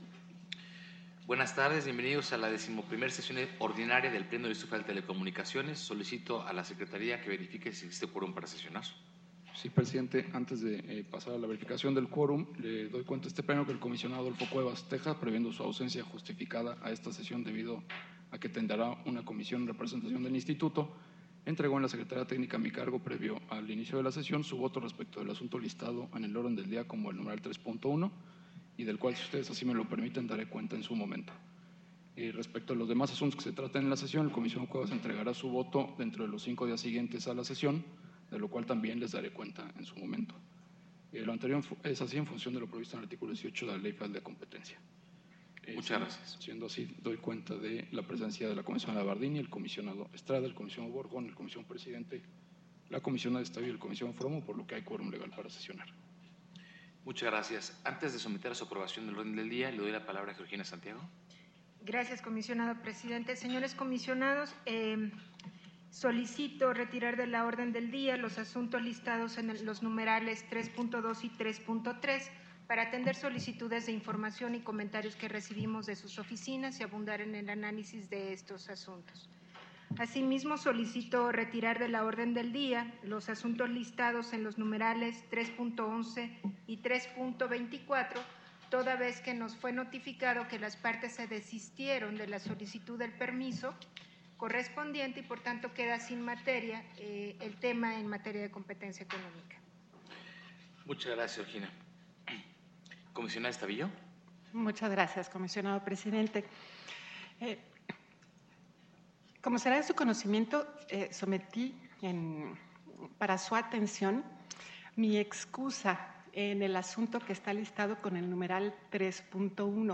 XI Sesión Ordinaria del Pleno 3 de septiembre de 2014 | Comisión Reguladora de Telecomunicaciones - IFT